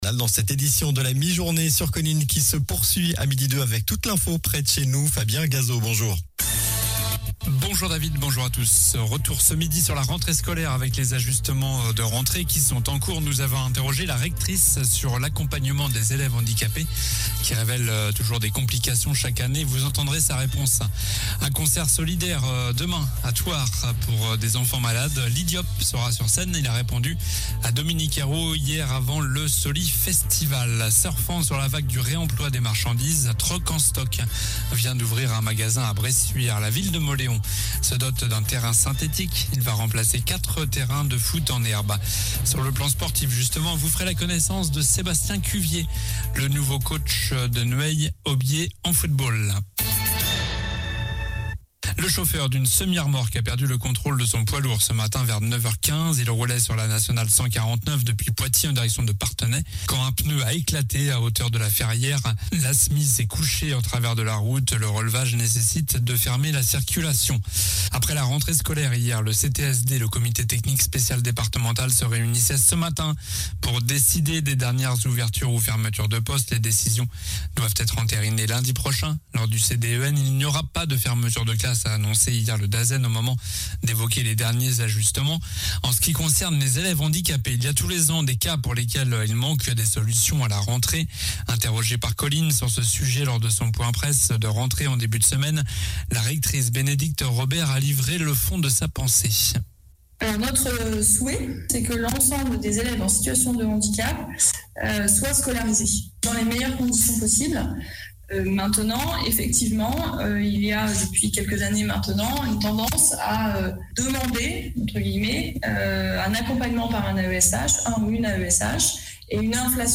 Journal du vendredi 2 septembre